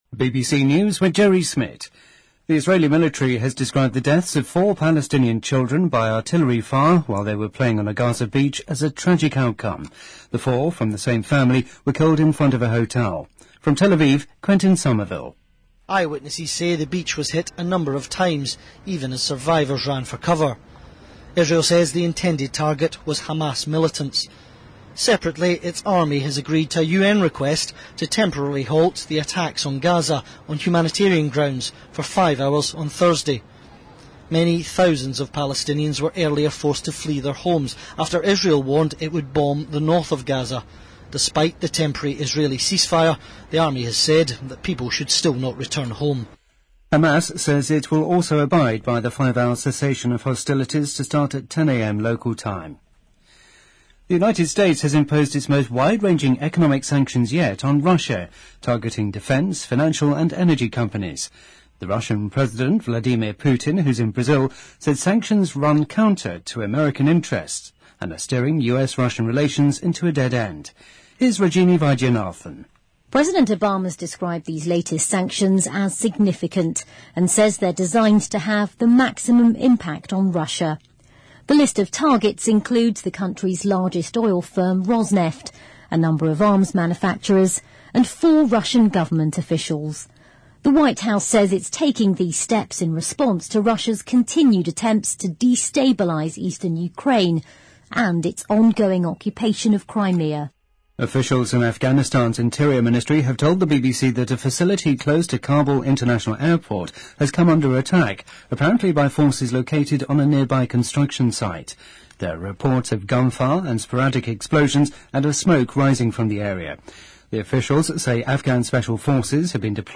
BBC news:美国在国防、金融和能源方面对俄罗斯实施最广泛的经济制裁|BBC在线收听